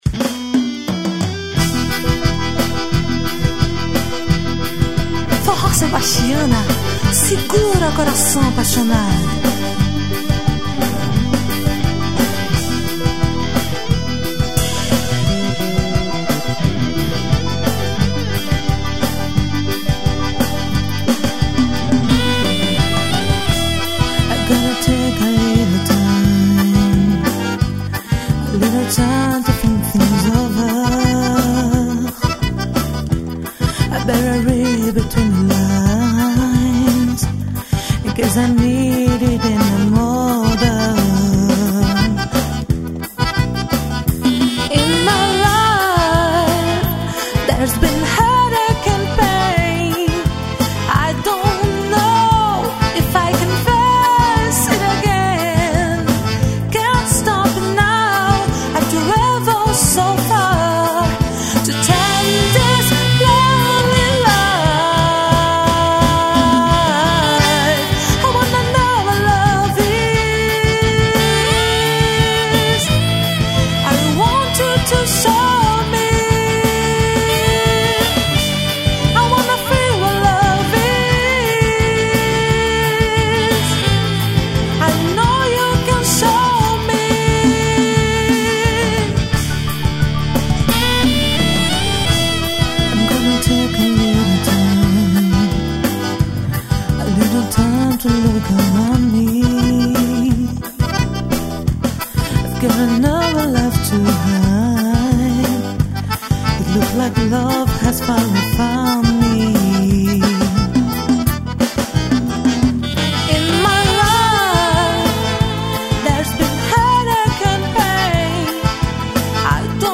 Ao Vivo Promocional.